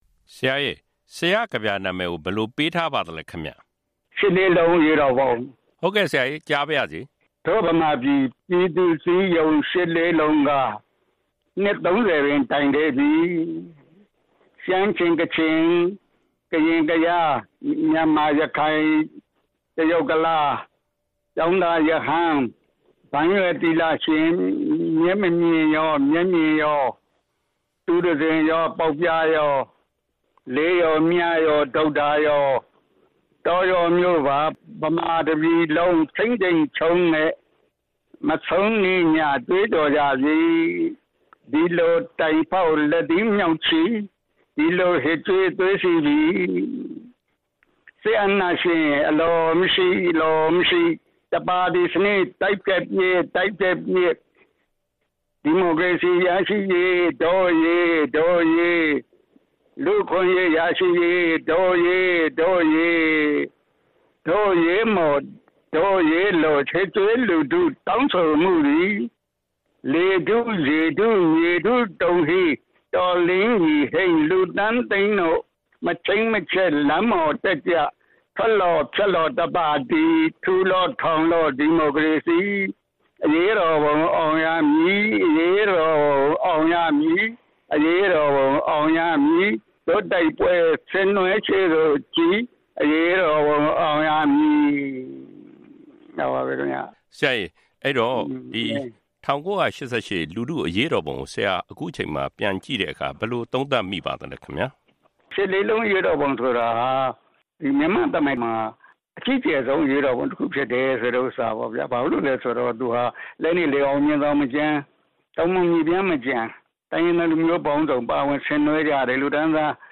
၁၉၈၈ လူထုအရေးတော်ပုံ နှစ် ၃ဝ ပြည့် အမှတ်တရအဖြစ် ပြည်သူ့ကဗျာ ဆရာကြီး မောင်စွမ်းရည်က ကဗျာတပုဒ် ရေးသားခဲ့ပါတယ်။ အဲဒီ “ရှစ်လေးလုံးအရေးတော်ပုံ” လို့ အမည်ပေးထားတဲ့ ကဗျာကို ဆရာက RFA အတွက် သူကိုယ်တိုင် ဖတ်ကြားပေးခဲ့ပါတယ်။ တဆက်တည်းမှာပဲ ဆရာ မောင်စွမ်းရည် က မြန်မာပြည်သူတွေအနေနဲ့ လက်ရှိအစိုးရအပေါ် အားမလို အားမရ ဖြစ်နေကြတာကို သူ့အနေနဲ့ စာနာနားလည်ကြောင်း၊ ဒါပေမဲ့ ဒီမိုကရေစီနည်းကျ ရွေးချယ်ခွင့် ရရှိနေတဲ့ အခွင့်အရေးတွေကို လက်လွတ်ဆုံးရှုံးမှု မရှိအောင် ထိန်းသိမ်းကြဖို့လိုကြောင်း စသဖြင့် ပြောကြားခဲ့ပါတယ်။